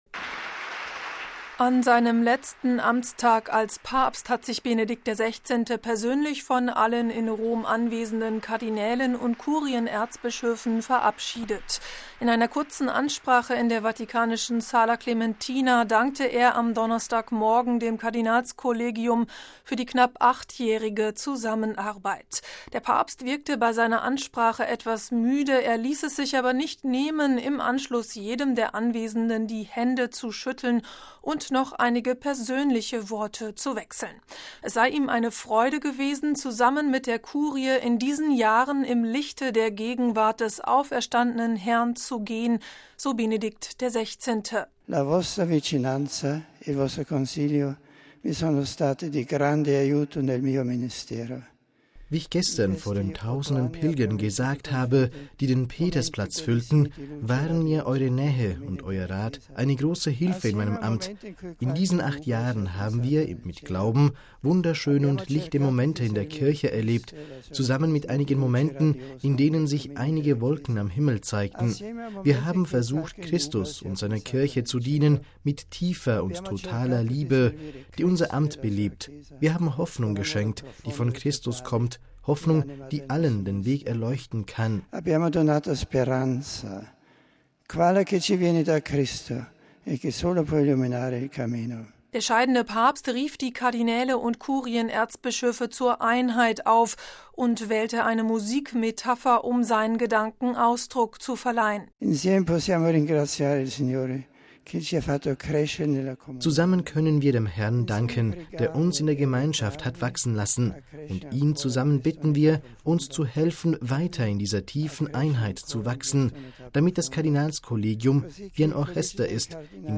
SRF-Interview